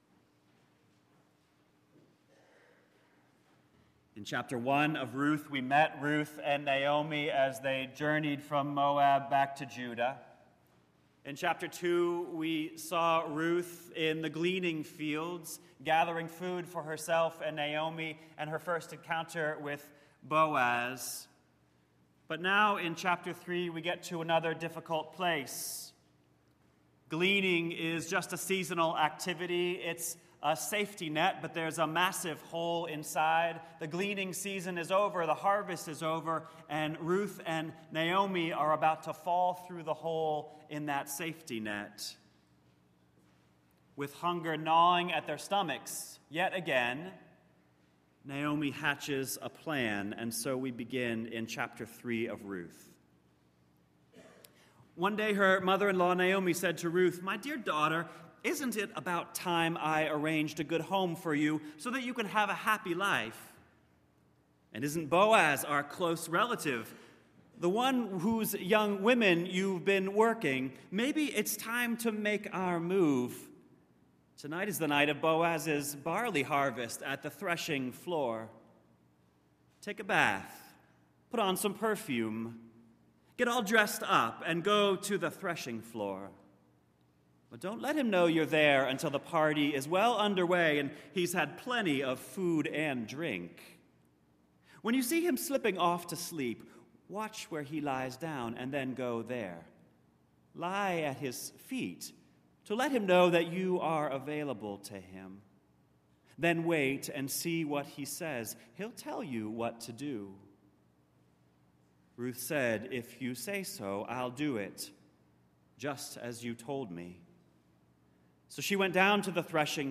The third Sunday in a 4-week sermon series on the Book of Ruth.